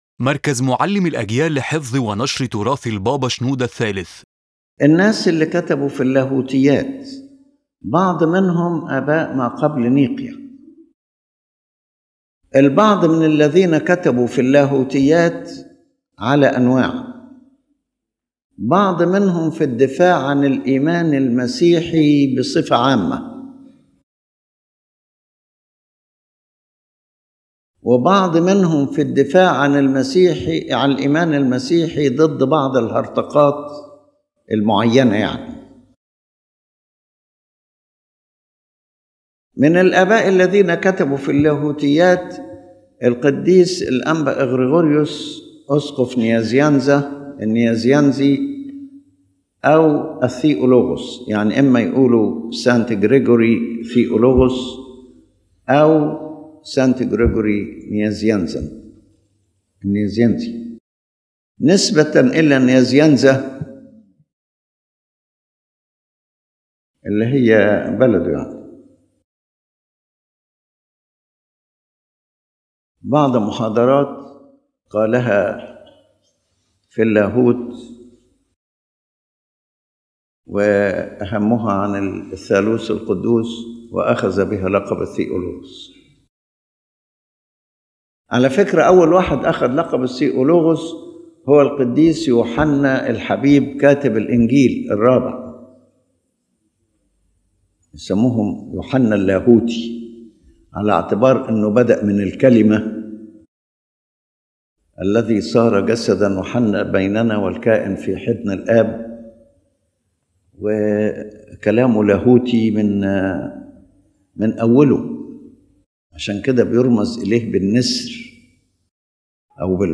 🔖 Brief Summary: The lecture stresses that the Church Fathers were striving defenders of faith and doctrine, and that reading them is not limited to the historical or academic aspect but is spiritual and educational nourishment requiring discerning insight and caution from errors and heresies.